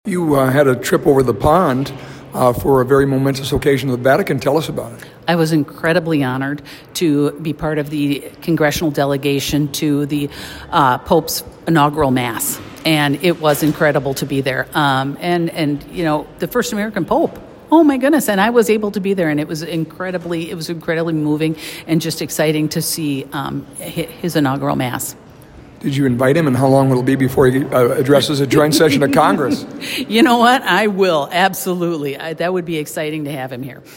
Congresswoman Michelle Fischbach reflects on visiting the Vatican for the installation mass for Pope Leo XIV